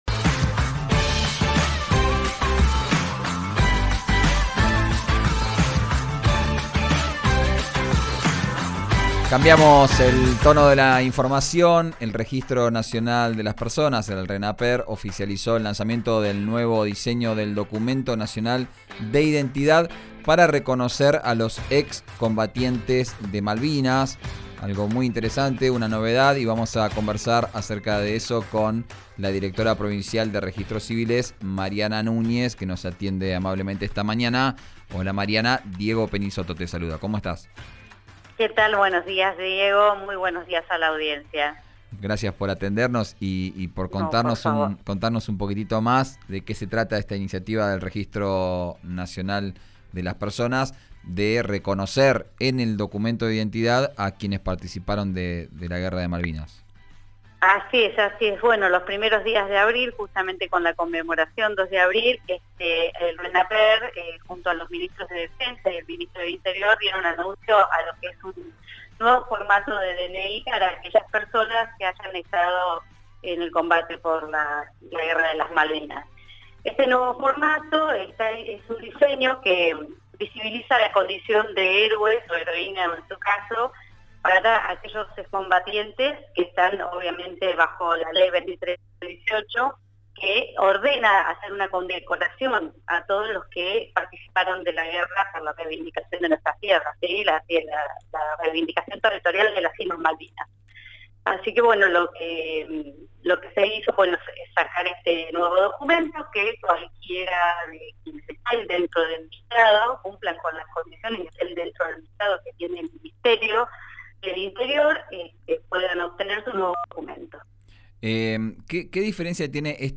Escuchá a la directora Provincial de Neuquén de Registros Civiles, Mariana Nuñez, en RÍO NEGRO RADIO: